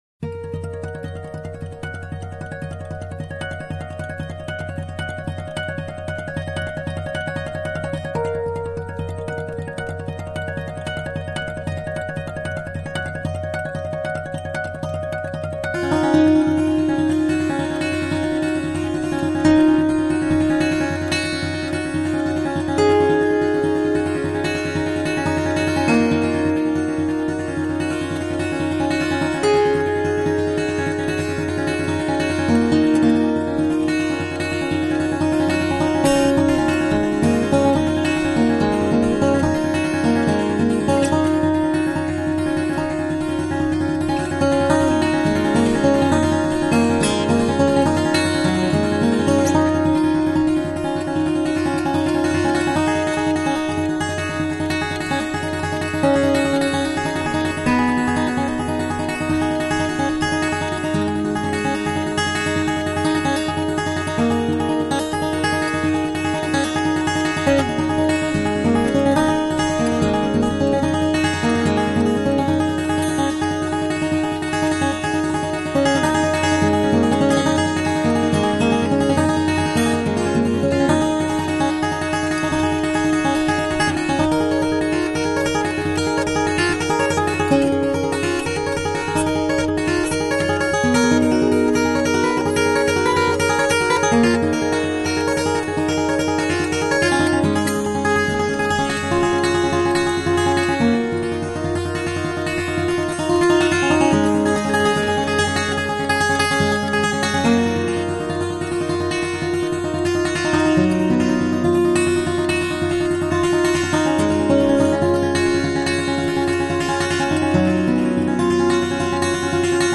高弦的音符有弹跳而出的立体感。中低弦和声呼应，柔情时丰泽润厚，激情时沉雄。